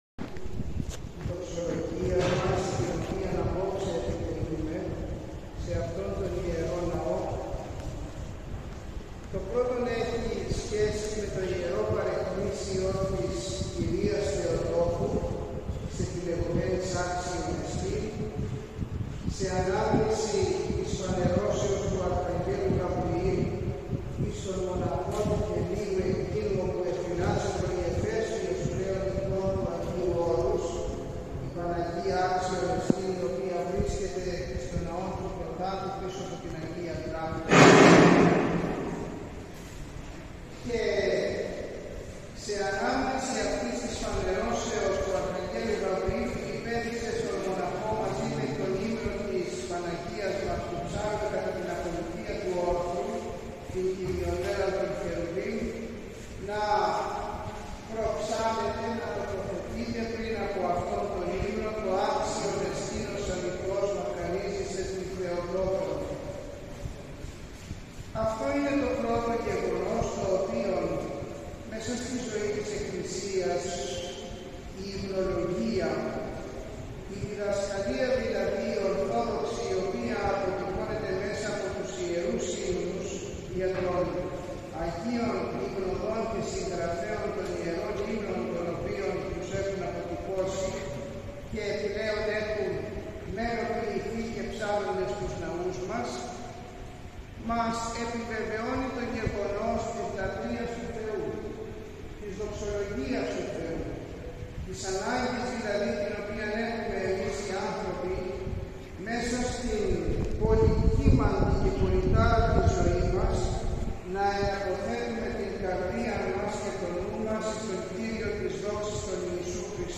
Την παραμονή της εορτής, Δευτέρα 10 Ιουνίου 2024 στις 8 μ.μ., τελέσθηκε Ιερά Αγρυπνία προεξάρχοντος του Σεβασμιωτάτου Μητροπολίτου Θεσσαλιώτιδος και Φαναριοφερσάλων κ. Τιμοθέου.
Στο κήρυγμα του ο Σεβασμιώτατος αναφέρθηκε στον βίο και τις αρετές του τιμωμένου Αγίου Λουκά, Αρχιεπισκόπου Συμφερουπόλεως και Κριμαίας, του ιατρού, ο οποίος όχι μόνο όταν ήταν στη ζωή, αλλά και μετά την κοίμησή του, συνεχίζει να βοηθάει τους ανθρώπους και με τη χάρη του Θεού να θαυματουργεί και τα θαύματά του μέχρι τις ημέρες μας είναι πάρα πολλά.